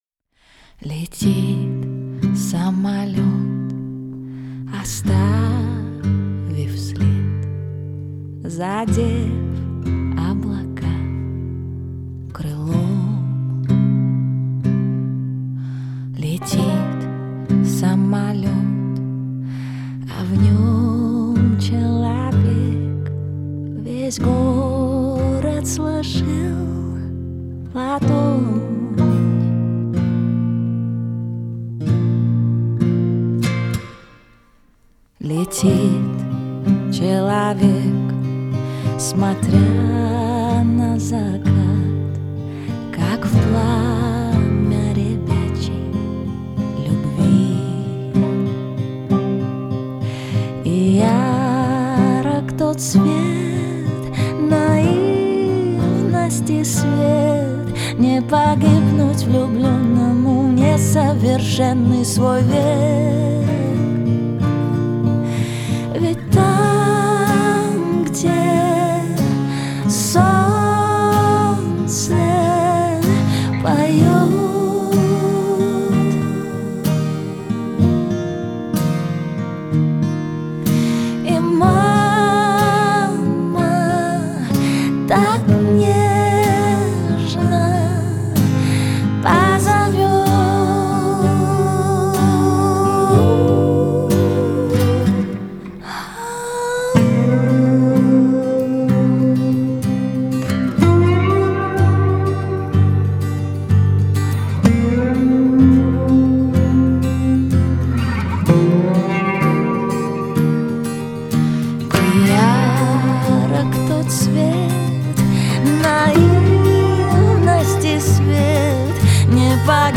выполненная в жанре поп с элементами этники.